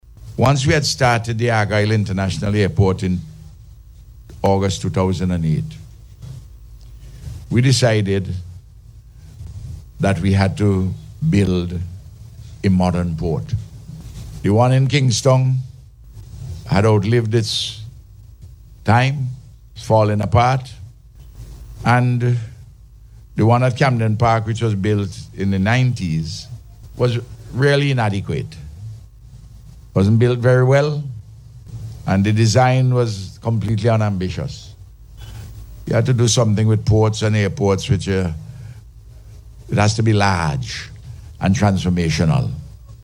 The Prime Minister was speaking at a ceremony on Monday where the Government received the keys to the newly completed Kingstown Port facilities.